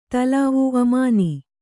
♪ talāvu amāni